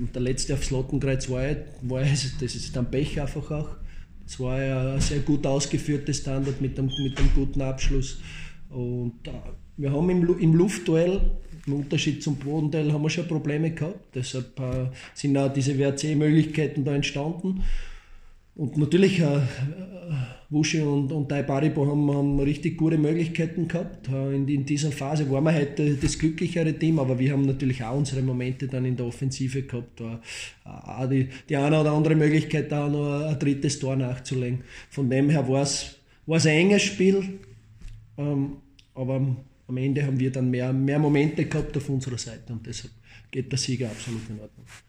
Stimmen: Wolfsberger AC vs. SK Sturm Graz